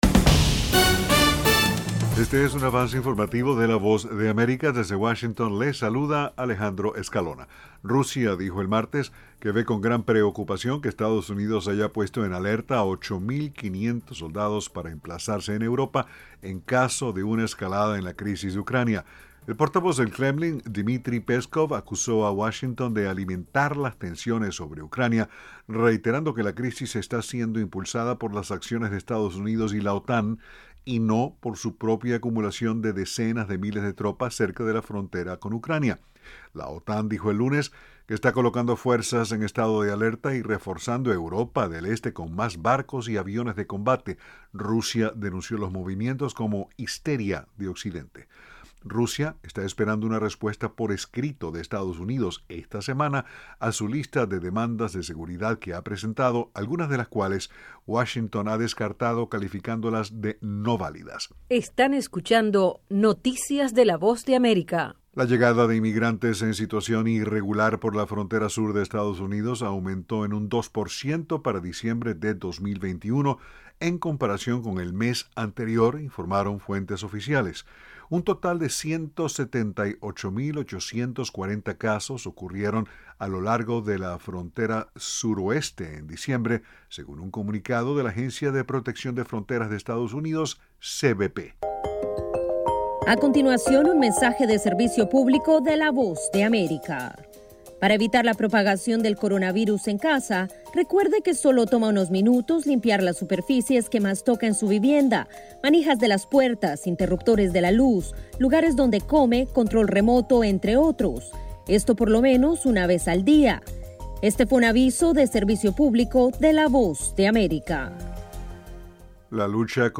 Avance Informativo 1:00pm